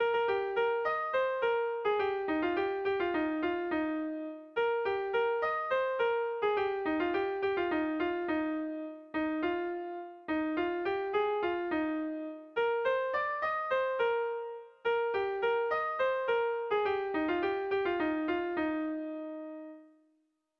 Zortzikoa, txikiaren moldekoa, 4 puntuz (hg) / Lau puntukoa, txikiaren modekoa (ip)
AABA